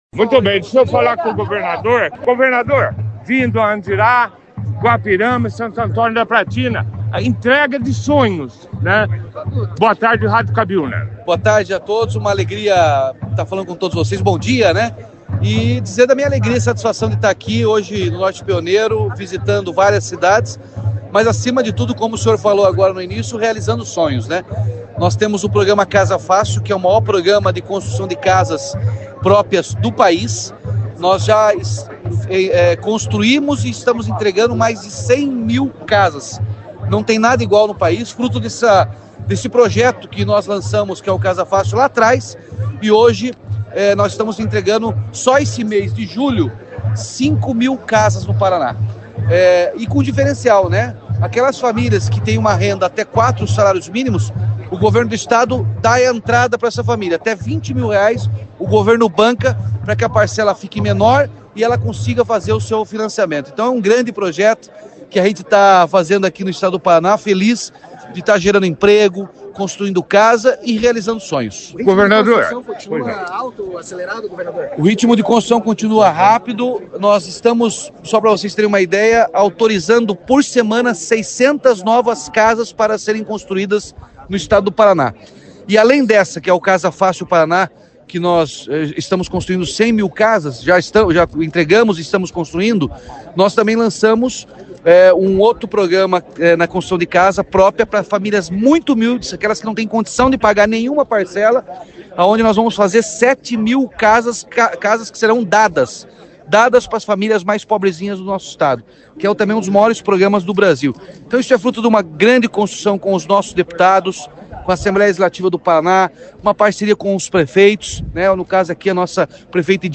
A entrega foi destaque na 2ª edição do Jornal Operação Cidade, com a participação do governador Ratinho Junior, dos secretários de Infraestrutura e Logística, Sandro Alex, e das Cidades, Guto Silva, além do deputado Luiz Claudio Romanelli, do presidente da Cohapar, Jorge Lange, e da ex-prefeita de Andirá, Ione Abib.